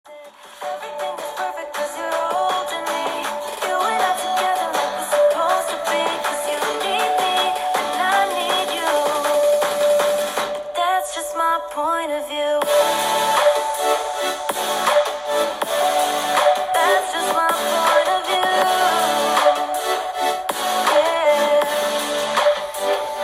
肝心の音については、とてもクリアで迫力あるサウンドを楽しめる印象です。
▼Xperia 1 IIIのフルステージステレオスピーカーの音はこちら！
クリアで臨場感あるサウンドかつ音の迫力もあって、左右の音のバランスも均一しっかりこだわりを感じられる仕上がりでした。